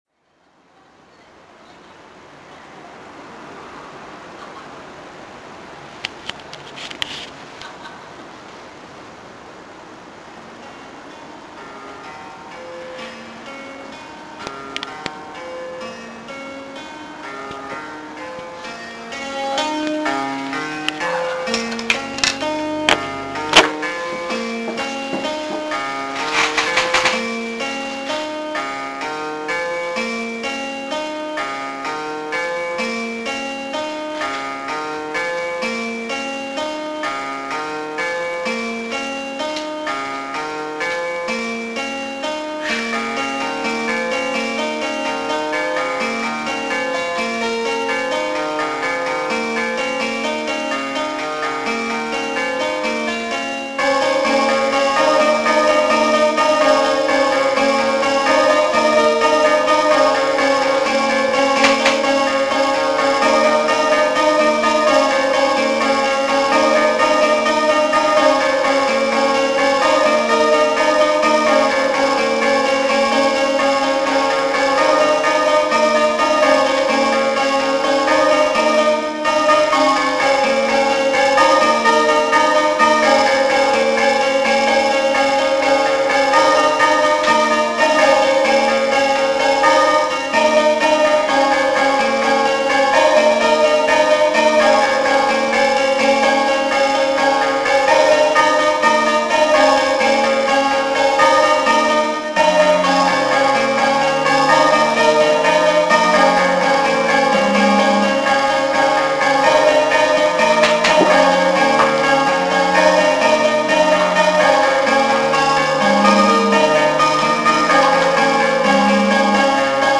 This is the first successful test of ChucK -> MIDI -> my new keyboard, a Roland VA-3 that I bought from a coworker.
Looking forward to exploring the cheesy options, this one has a big tone bank.
Filed under: Instrumental | Comments (1)